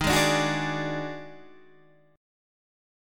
D# Minor Major 7th Double Flat 5th